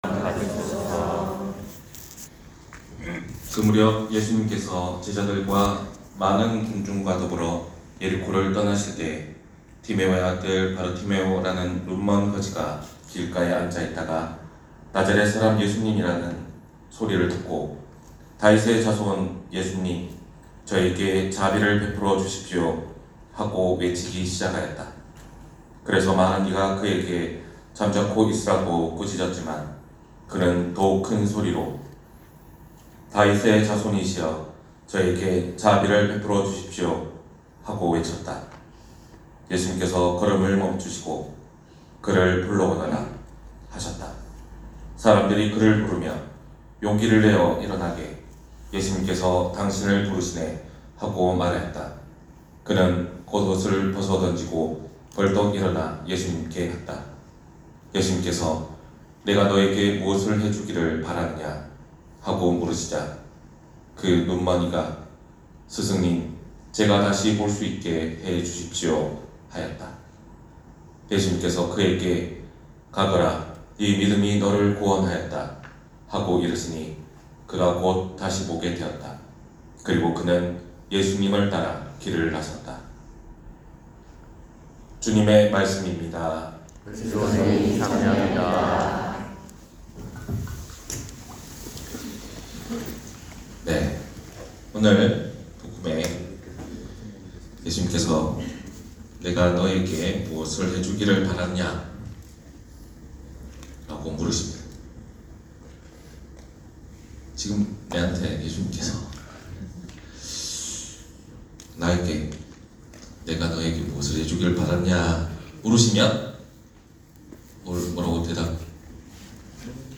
241027신부님강론말씀